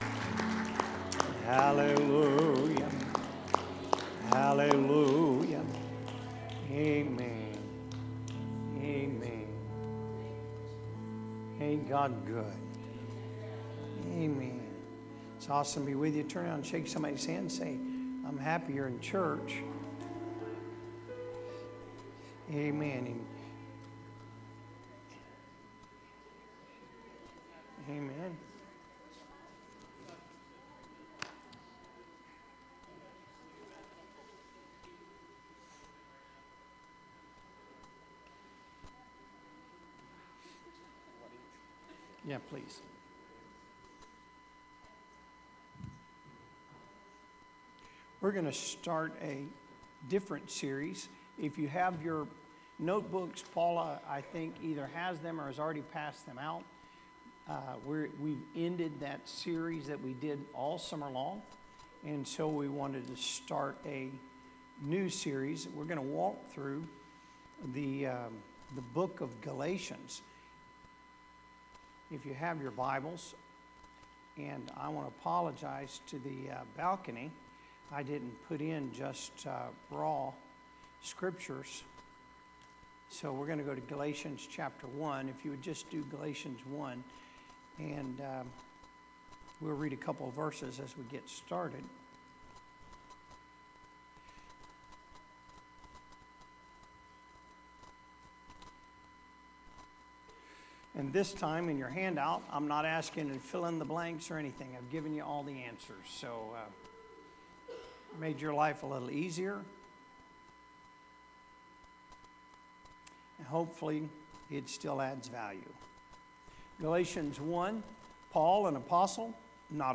Wednesday Service -Gospel to the Galatians